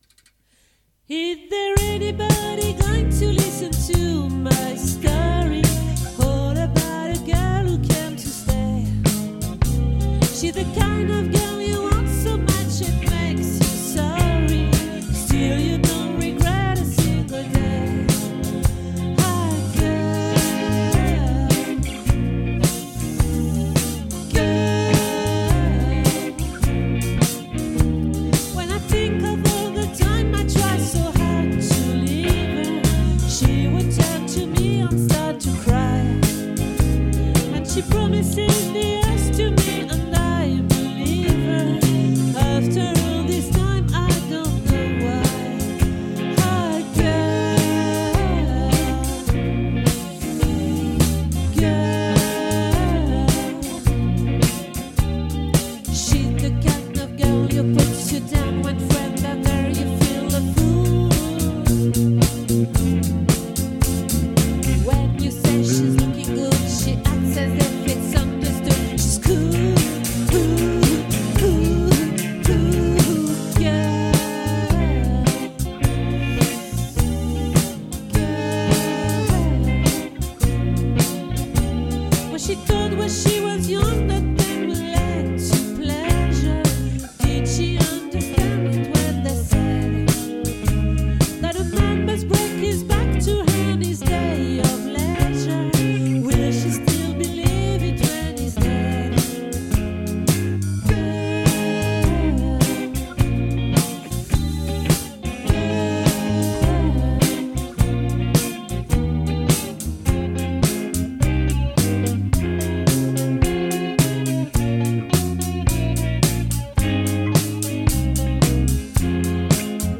🏠 Accueil Repetitions Records_2024_02_20_OLVRE